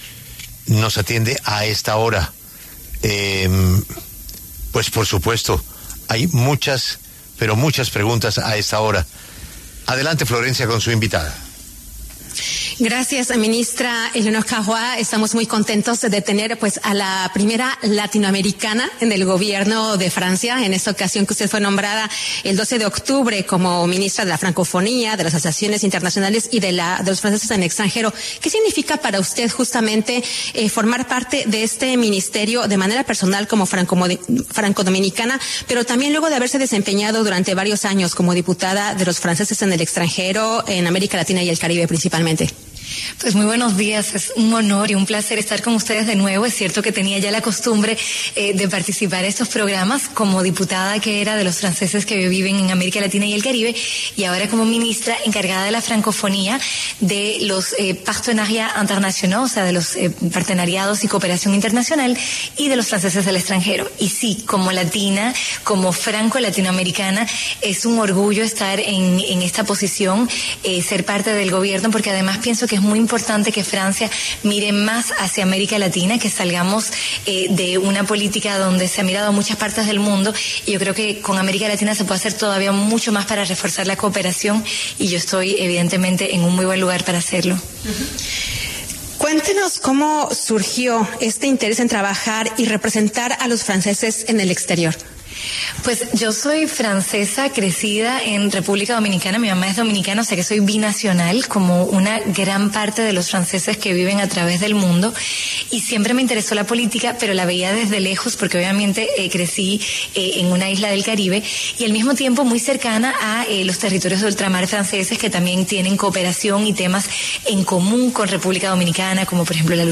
Éléonor Caroit, ministra de la Francofonía, de las Asociaciones Internacionales y de los Franceses en el extranjero, pasó por los micrófonos de La W y contó su historia al ser la primera latinoamericana en formar parte del Gobierno de Francia.